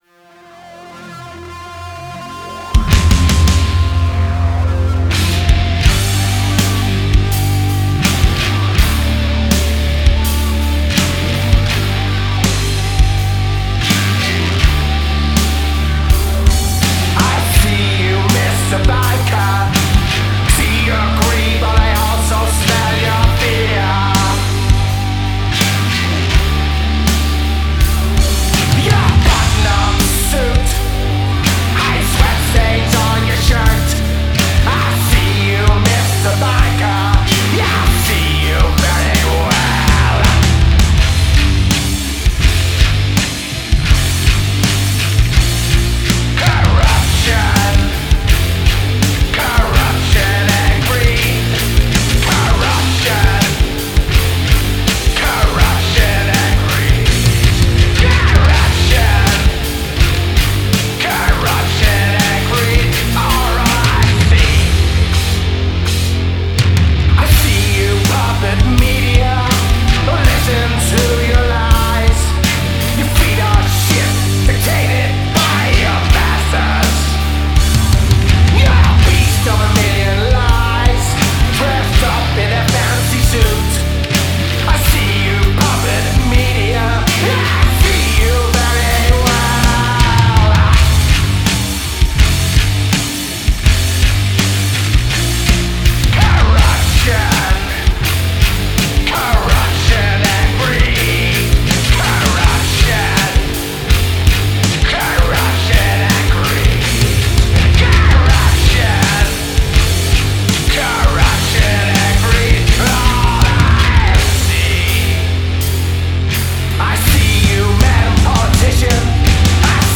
I think the mix sounds pretty good however my singer thinks it's lacking something but he can't tell me what lol! It was mixed in Reaper and mastered using Ozone 10.